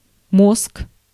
Ääntäminen
US : IPA : [ˈbreɪn]